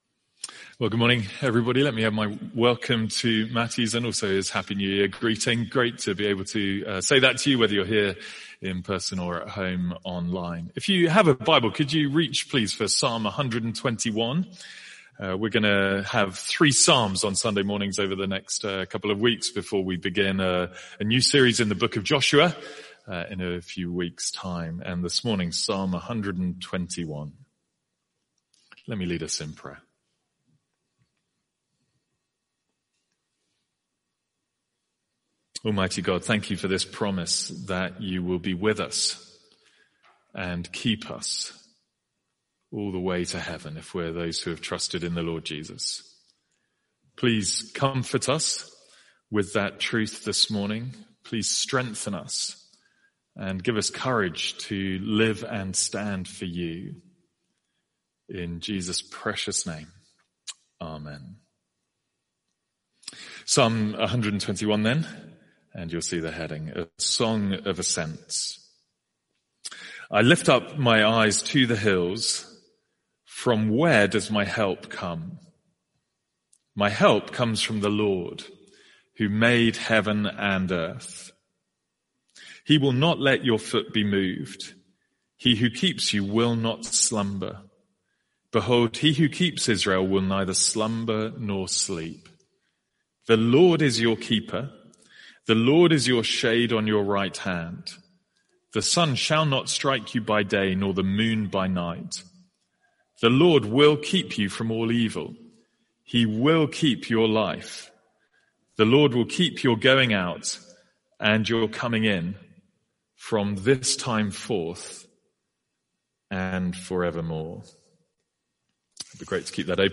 A one off sermon from our morning services.